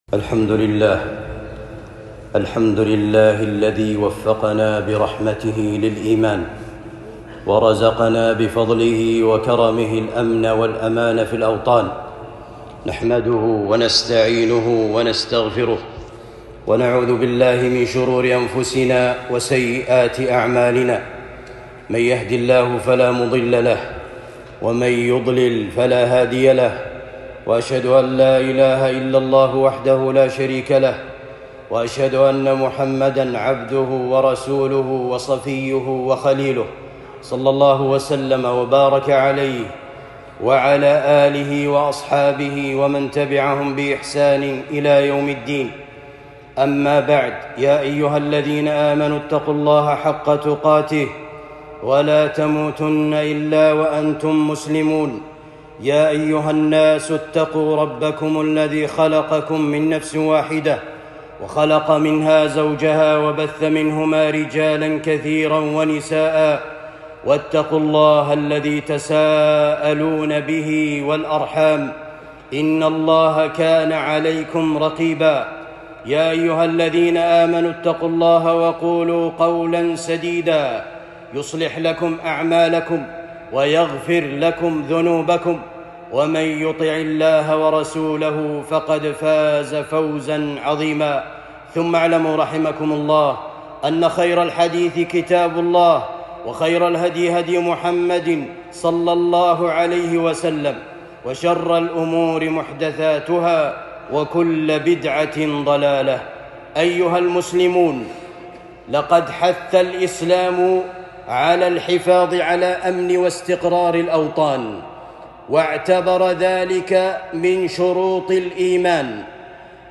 الخطب